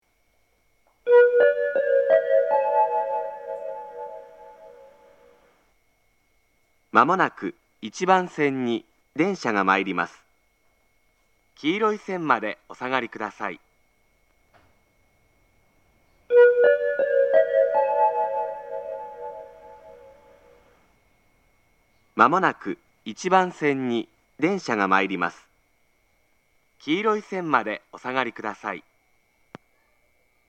仙石旧型（男性）
仙石旧型男性の接近放送です。同じ内容を2度繰り返します。